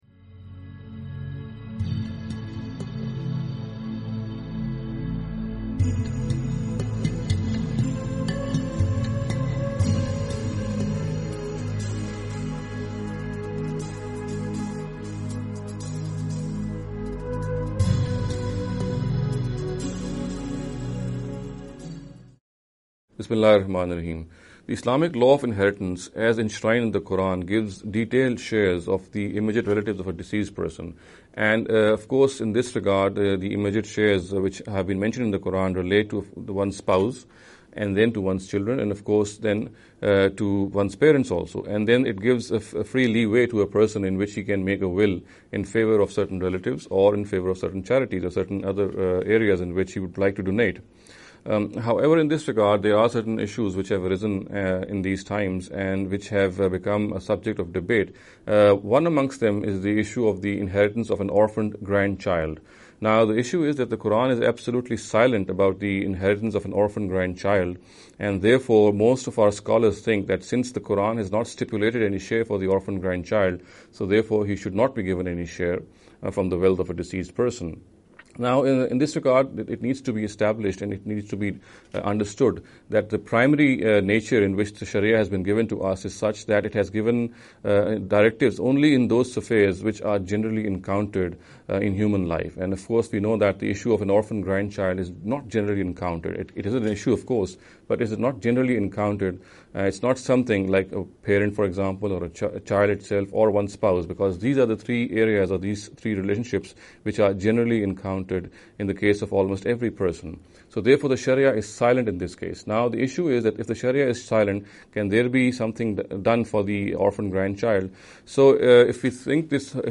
This lecture series will deal with some misconception regarding the Economic Directives of Islam.